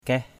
kaih.mp3